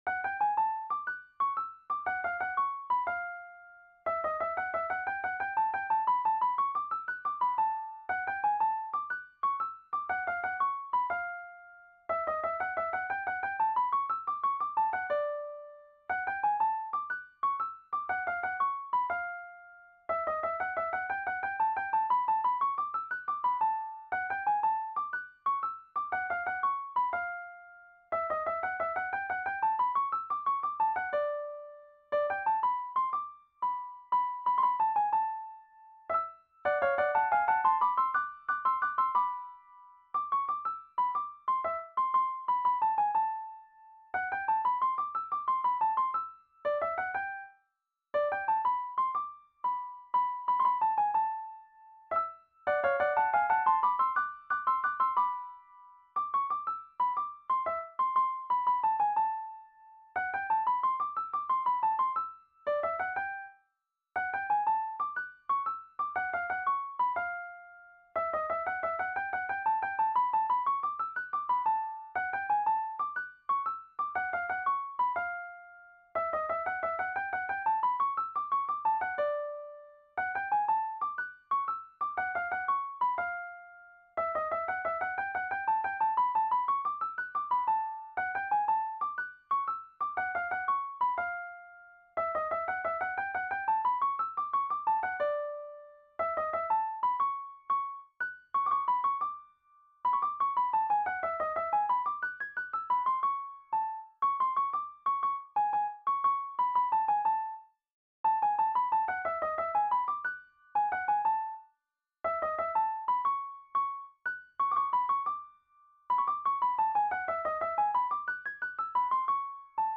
danse : pas de quatre